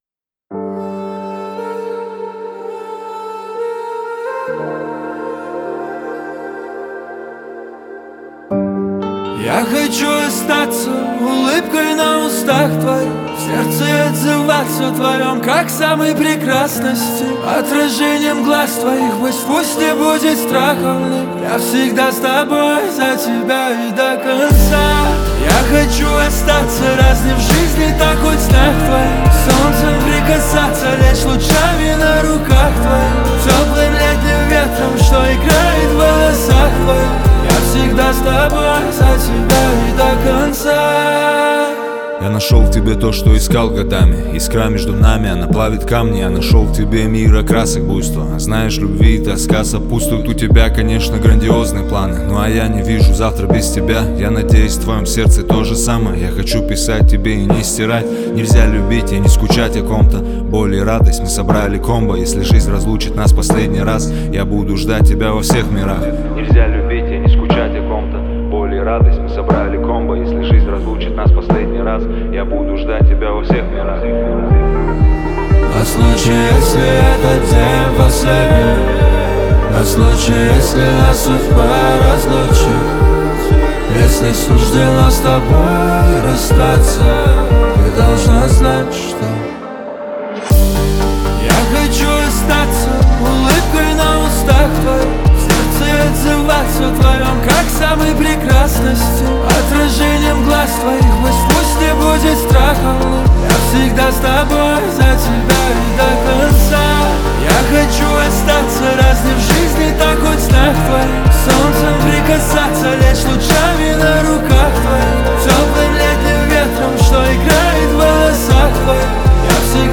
• Жанр: Русские